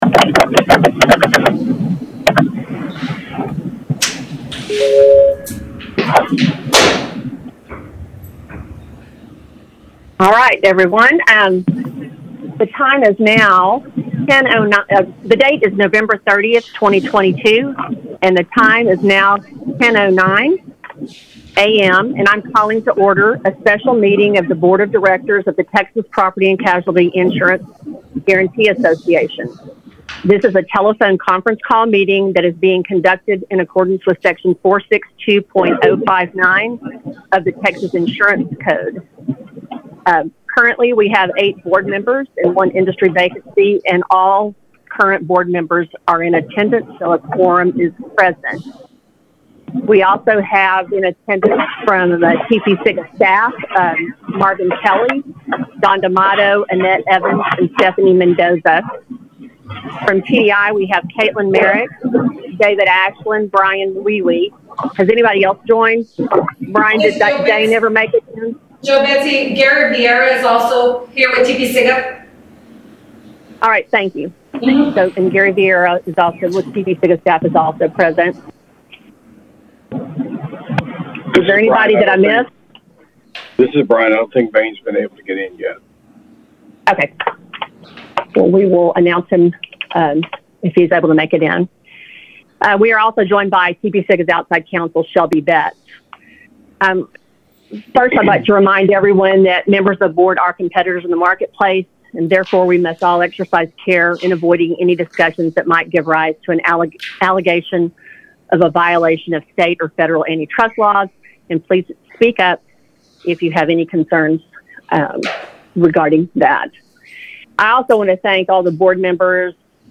TPCIGA-Board Meetings
Meetings are open to the public and are held at the Association offices located at 9120 Burnet Road, Austin, Texas.